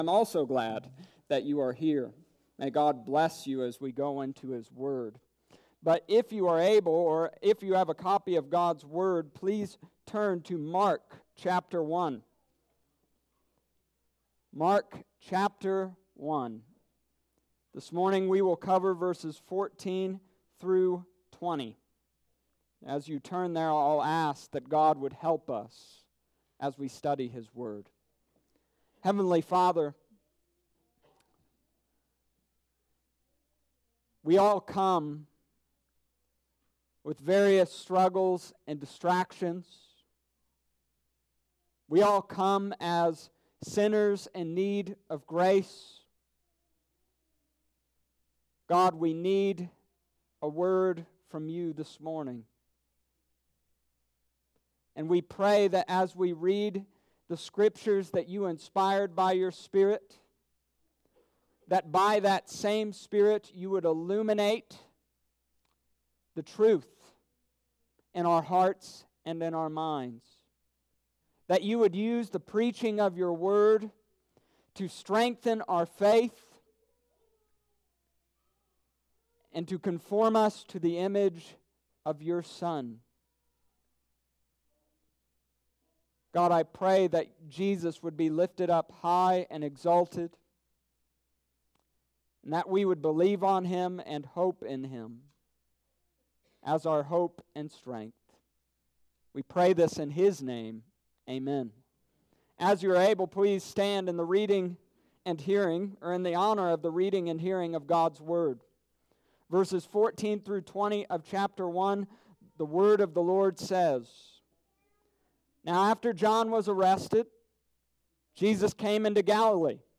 Summary of Sermon: This week we discussed the preaching and preachers of Jesus.